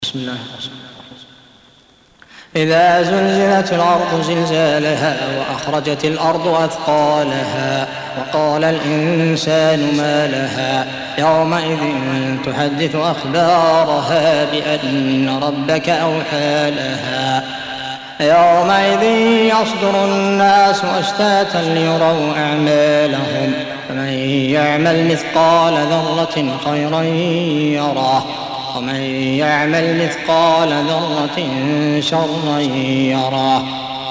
سورة الزلزلة Audio Quran Tarteel Recitation
Surah Repeating تكرار السورة Download Surah حمّل السورة Reciting Murattalah Audio for 99. Surah Az-Zalzalah سورة الزلزلة N.B *Surah Includes Al-Basmalah Reciters Sequents تتابع التلاوات Reciters Repeats تكرار التلاوات